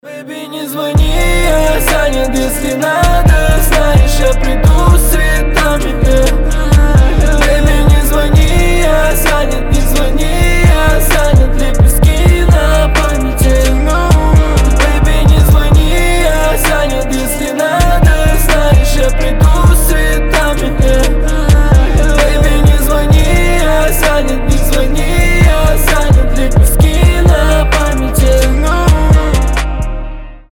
• Качество: 320, Stereo
мужской вокал
лирика
грустные